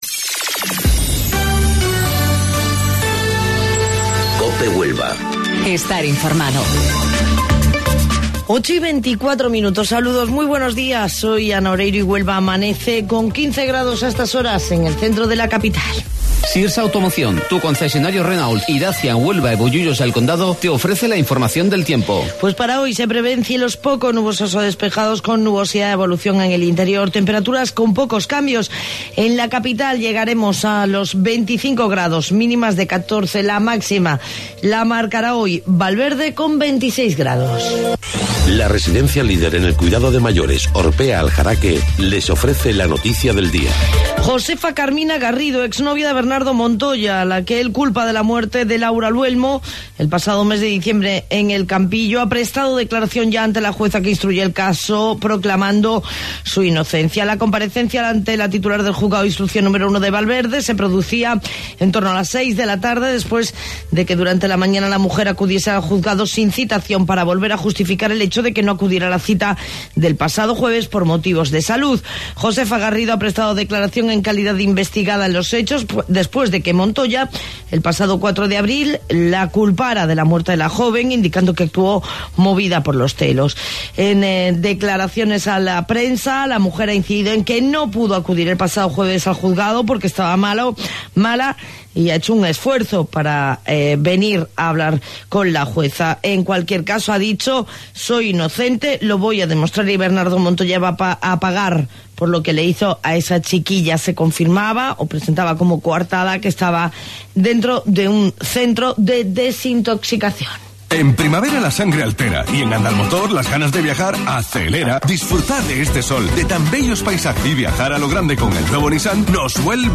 AUDIO: Informativo Local 08:25 del 21 de Mayo